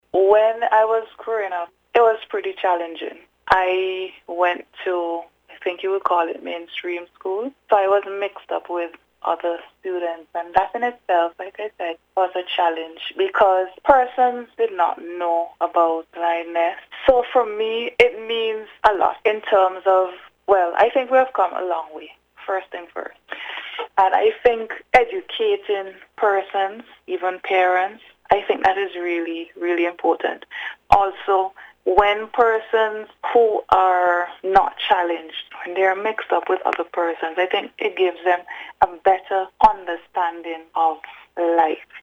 During an interview with NBC News